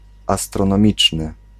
Ääntäminen
US : IPA : /ˌæstrəˈnɑːmɪkəl/